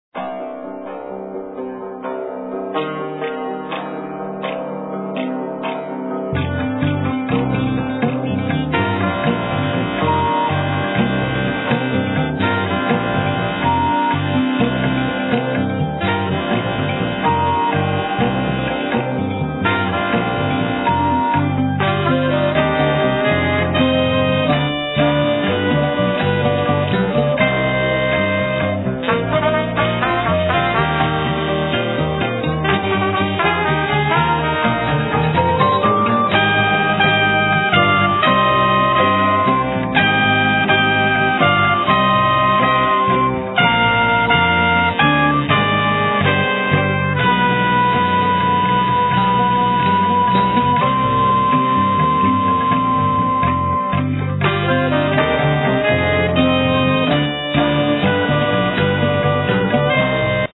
Cimbaloom, Vocals, Drums
Tenor saxophone
Percussions
Flute
Trumpet
Trombone
Bass
Tablas
Alt saxophone